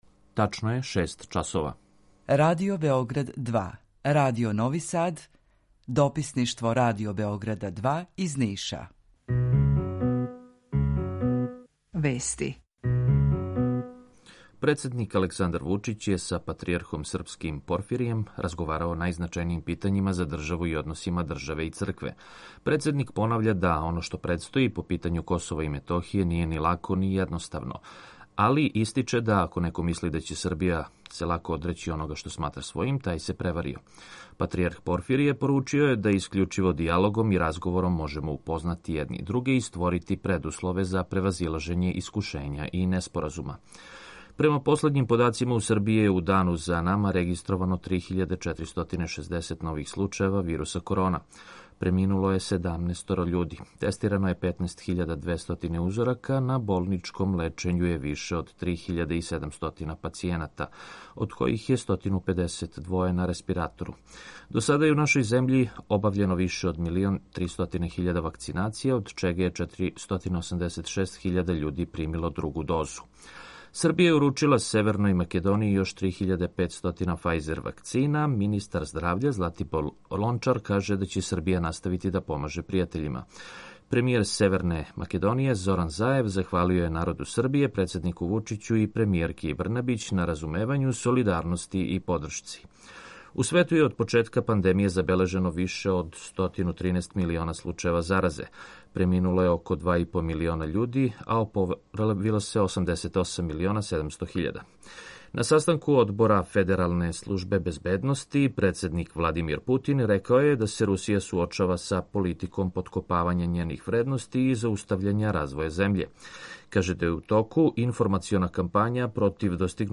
Jутарњи програм заједнички реализују Радио Београд 2, Радио Нови Сад и дописништво Радио Београда из Ниша.
У два сата, ту је и добра музика, другачија у односу на остале радио-станице.